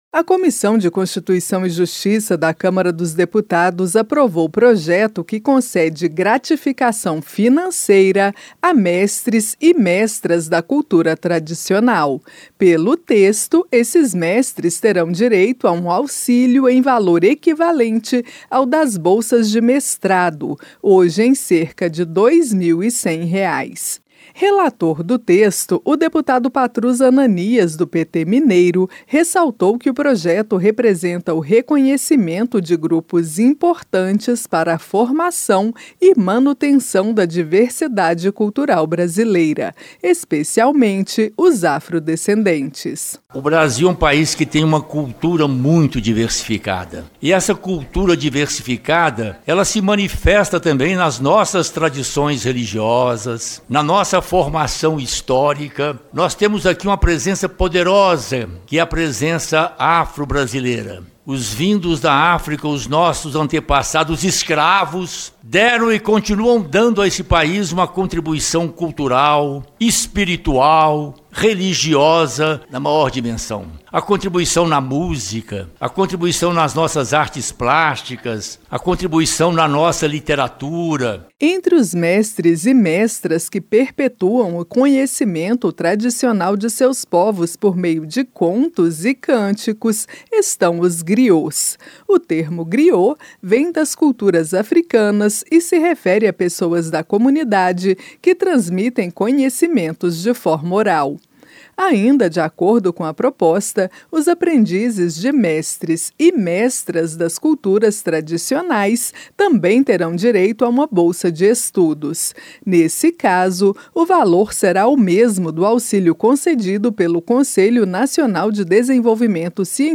Comissão aprova concessão de bolsas a mestres da cultura tradicional - Radioagência